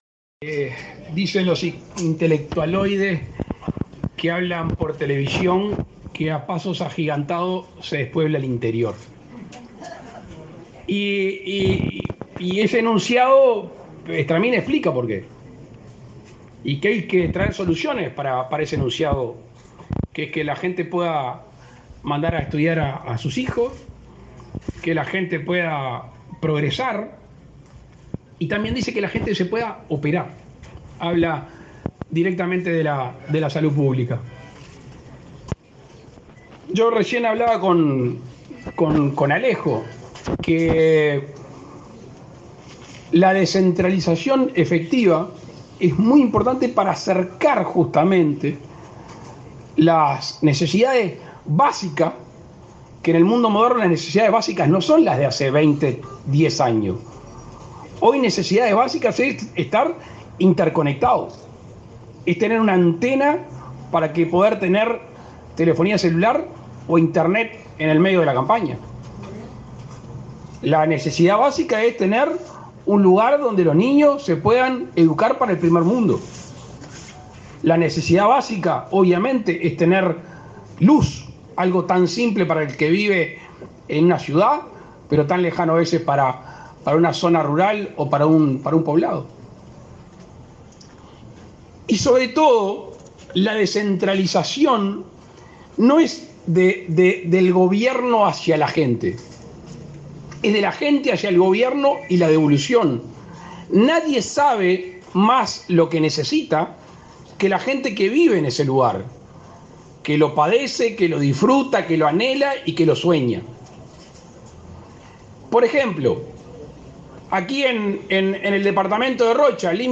Palabras del presidente Luis Lacalle Pou
Palabras del presidente Luis Lacalle Pou 20/04/2023 Compartir Facebook X Copiar enlace WhatsApp LinkedIn El presidente de la República, Luis Lacalle Pou, encabezó en Rocha el acto de inauguración del centro de terapia intensiva (CTI) y el servicio de nefrología del hospital departamental.